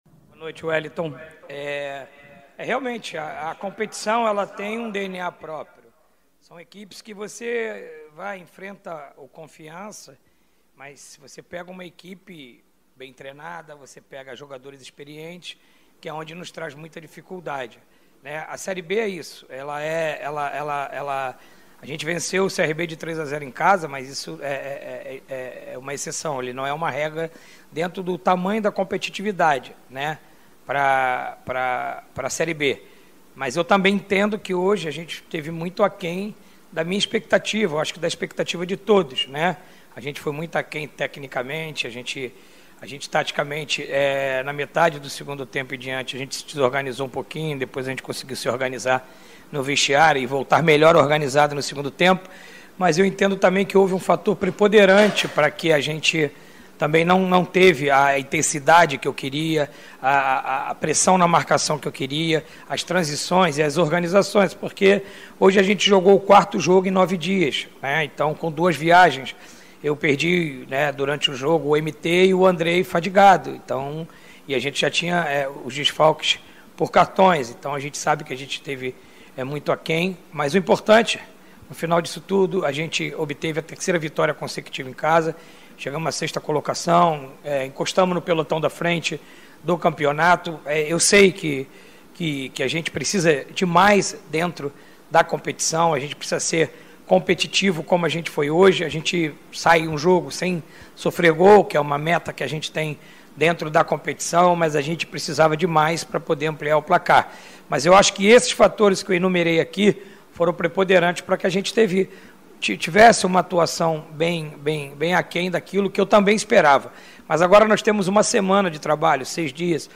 Na entrevista coletiva, o técnico Marcelo Cabo, vibrou com a vitória e destacou o desgaste físico que o Vasco sofreu diante do Goiás em Goiânia no meio de semana tendo que atuar 90 minutos com 10 jogadores devido à expulsão de Bruno Gomes com apenas 5 minutos de partida.